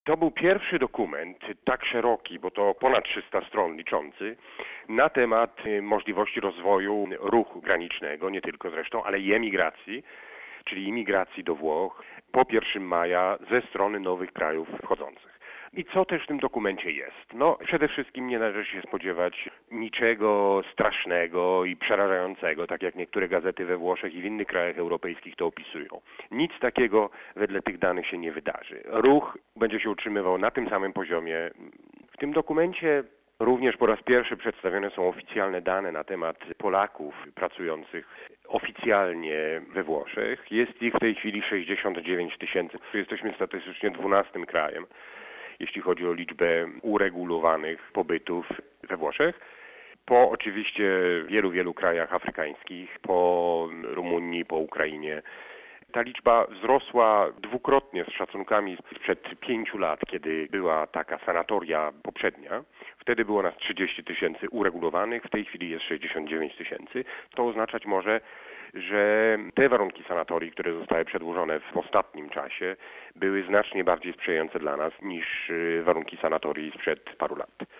Dokument o rozwoju ruchu granicznego po 1 maja ze strony nowych krajów wchodzących do Unii Europejskiej zaprezentowano w Rzymie 27 kwietnia. Mówi jeden z prelegentów, ambasador RP przy Kwirynale, Michał Radlicki. jp